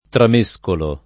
tramescolo [ tram %S kolo ]